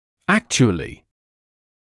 [‘ækʧuəlɪ][‘экчуэли]фактически, на самом деле, действительно